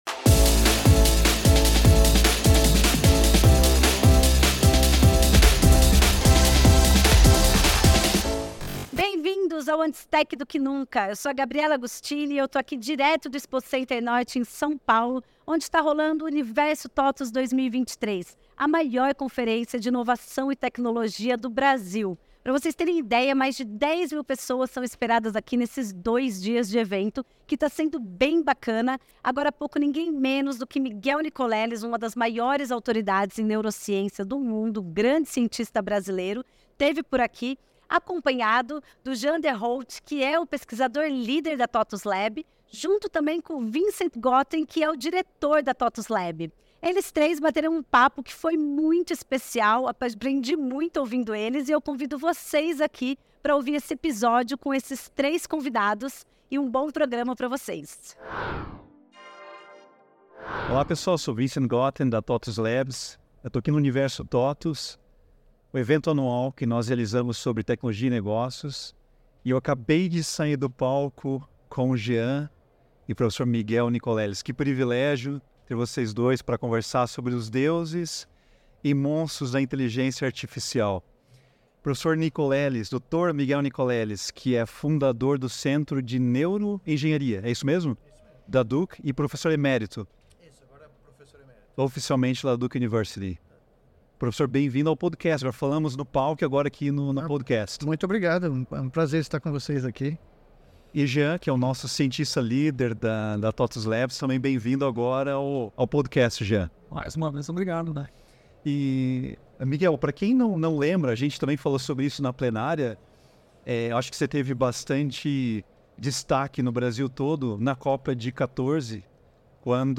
gravado durante o Universo TOTVS 2023